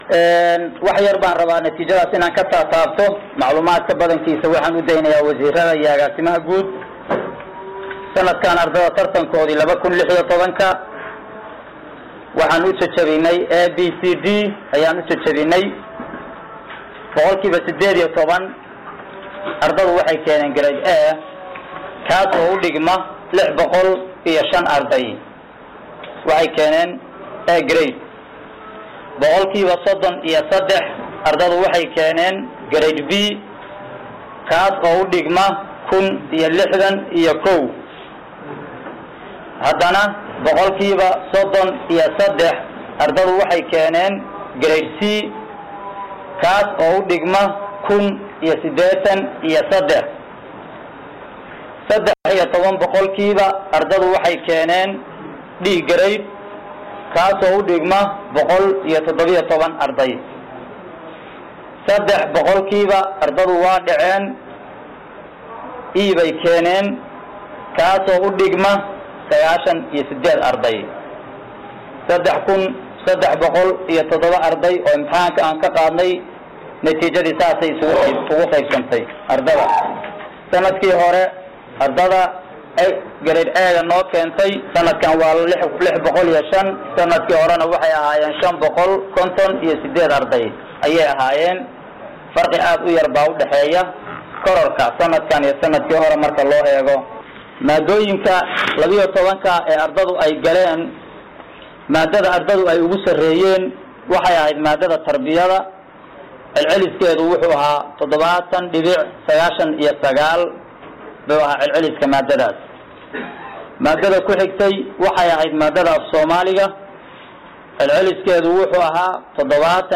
Munaasibad Ballaaran oo lagu soo bandhigayey Natiijada Imtixaankii Dugsiyada sare ee Puntland ayaa maanta waxaa lagu qabtay xarunta dhexe ee Wasaaradda ee magaalada Garoowe waxaa ka qaybalay Wasiirka Wasaaradda Waxbarashada iyo Tacliinta Sare ee Puntland Proff. Abshir Aw-yuusuf Ciise, Wasiir ku xigeenka   Dr. Maxamed Cali Faarax, Agaasimaha Guud, Agaasimaha Waaxda Imtixaanaadka, Macallimiinta Imtixaanka saxayey, iyo maamuleyaasha Dugsiyada sare ee Puntland.
Hoos ka dhagayso  hadalka mas’uuliyiinta Wasaaradda